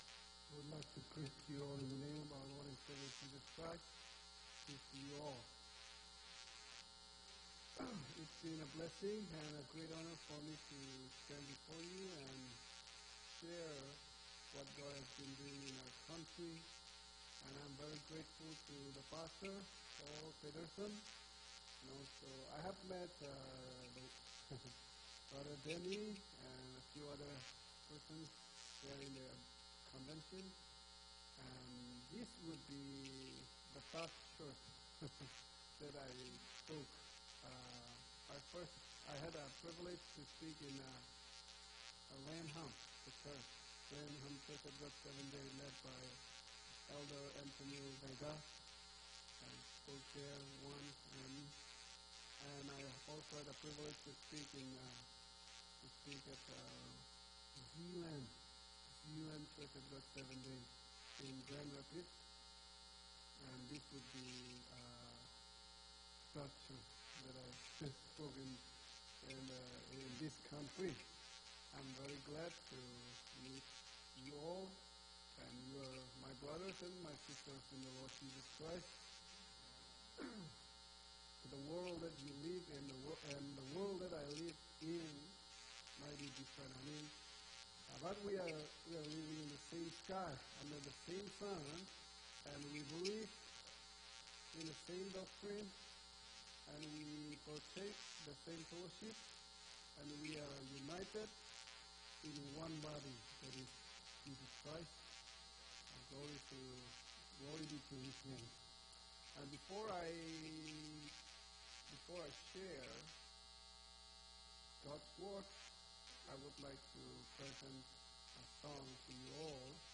7-15-17 sermon